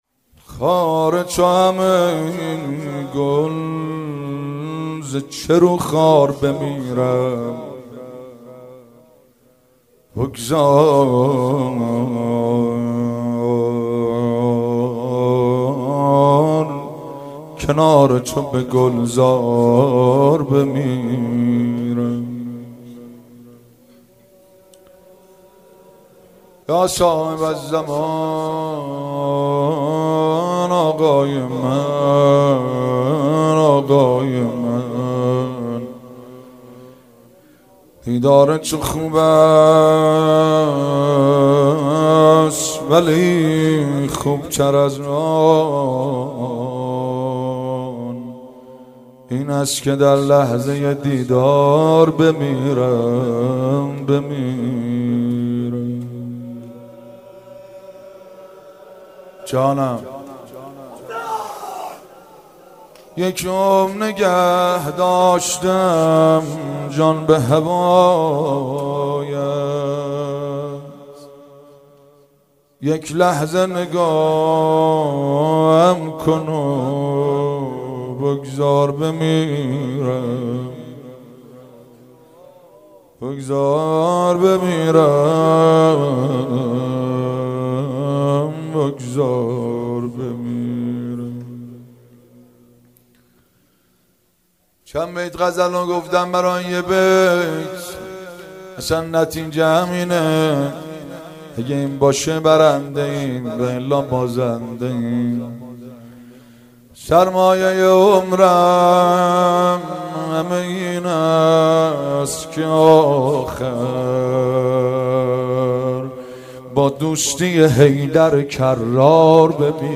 قالب : مناجات